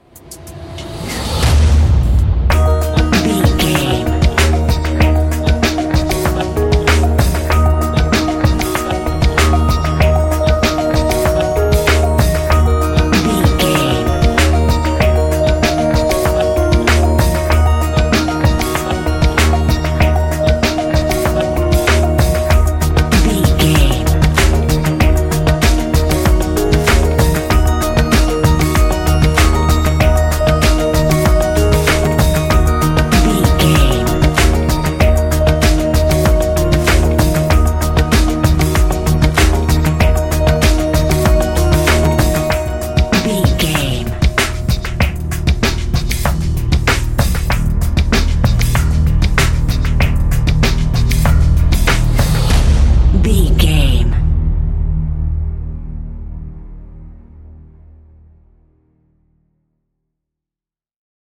Ionian/Major
D♯
techno
trance
synthesizer
synthwave